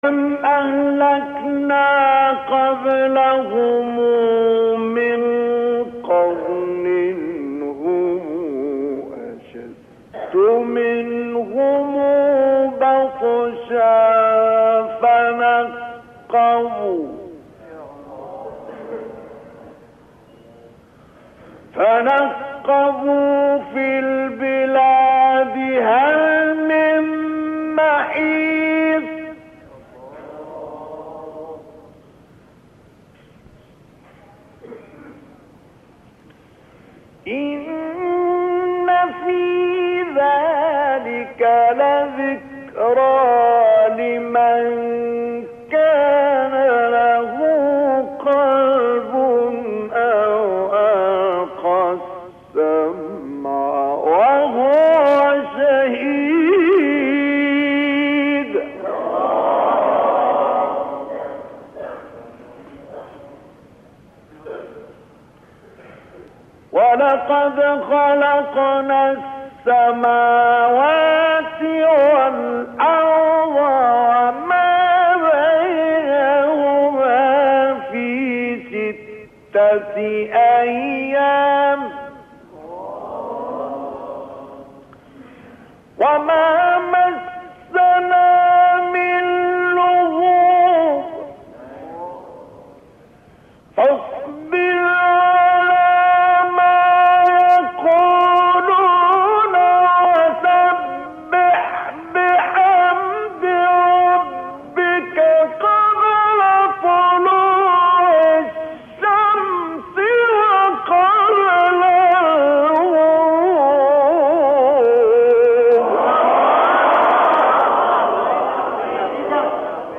سایت قرآن کلام نورانی - نهاوند مصطفی اسماعیل (3).mp3
سایت-قرآن-کلام-نورانی-نهاوند-مصطفی-اسماعیل-3.mp3